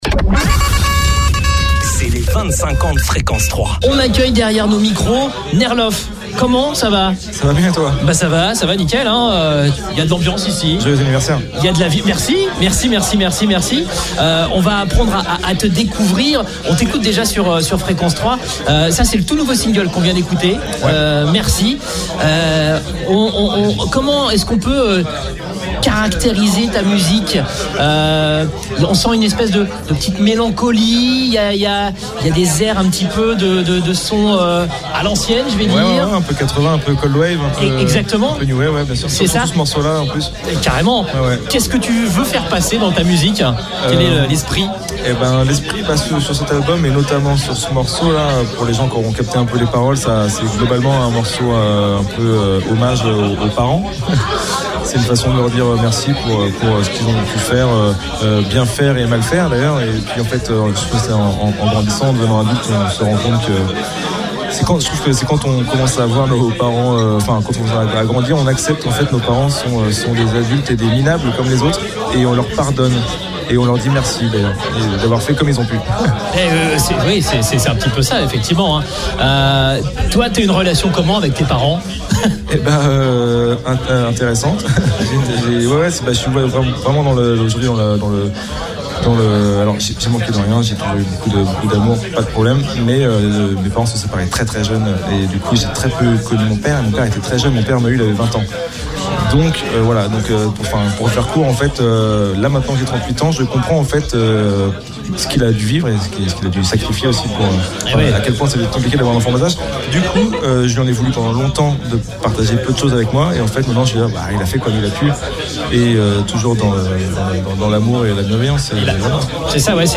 Rencontre avec un artiste qui n’a pas peur de se mettre à nu.